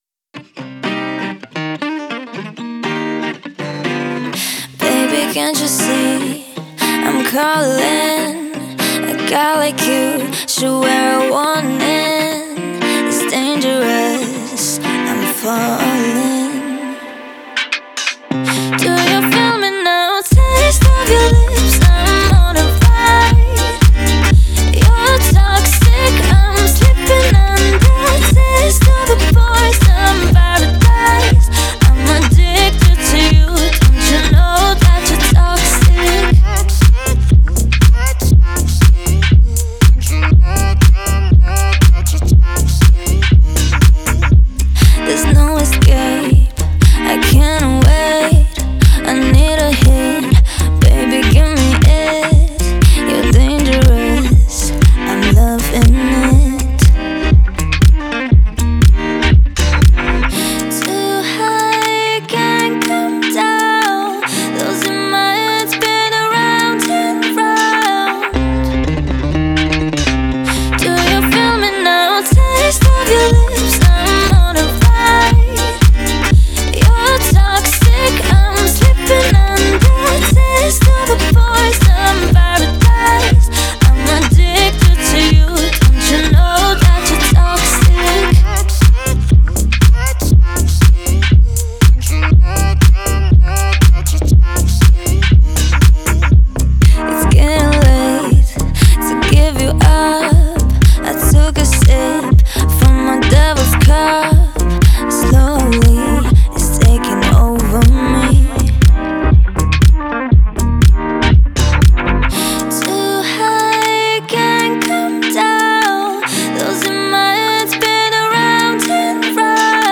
запоминающимся битом